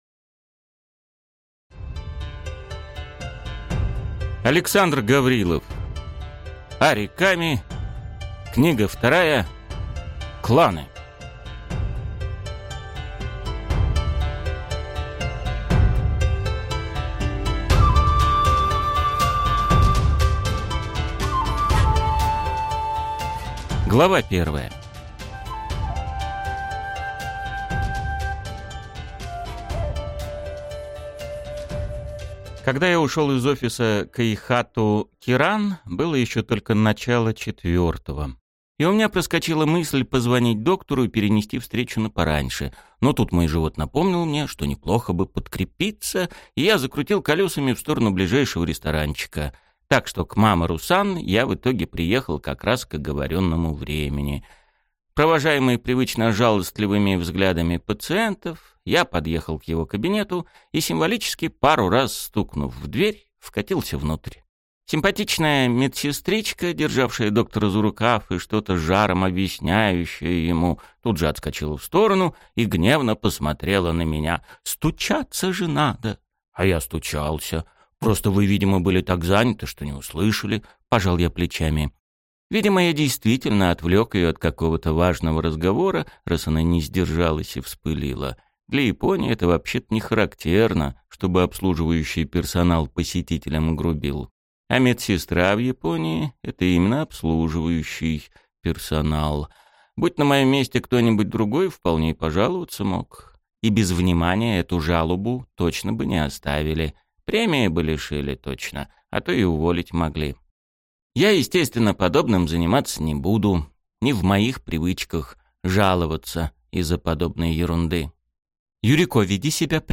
Аудиокнига Ари Ками. Книга 2. Кланы | Библиотека аудиокниг